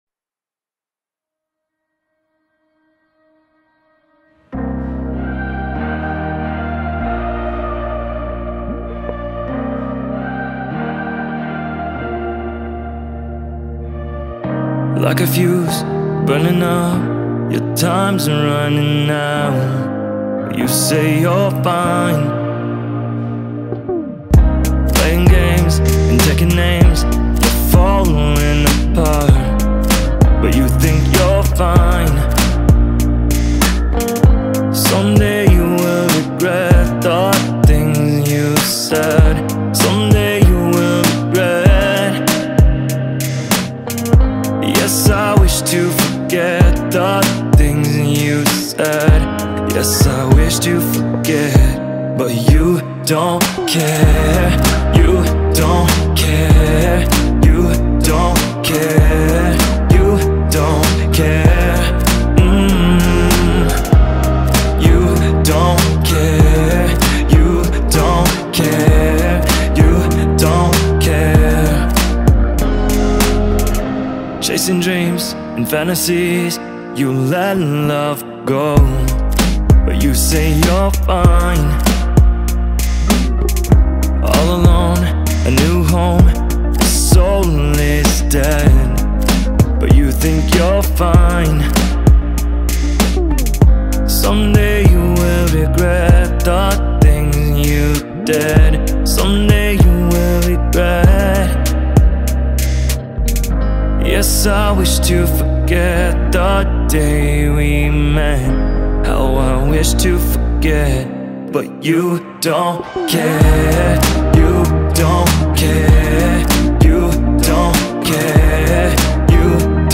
BPM: 97